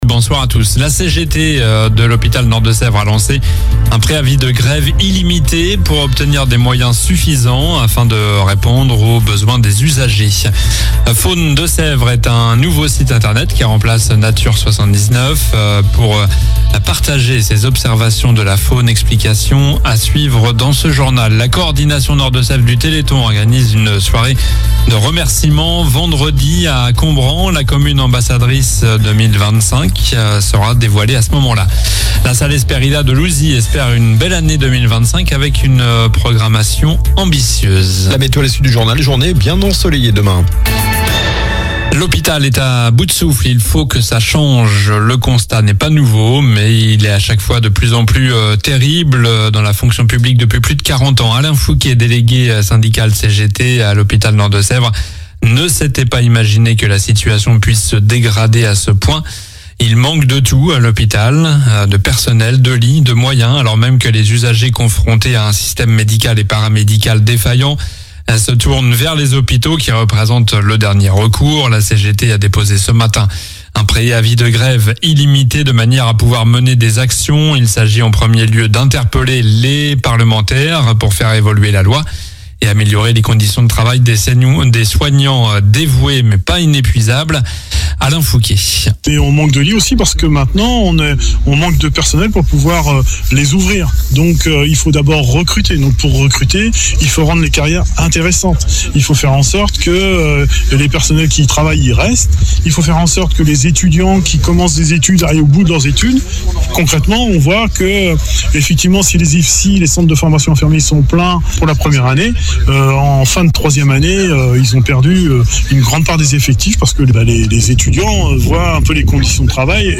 Journal du lundi 3 mars (soir)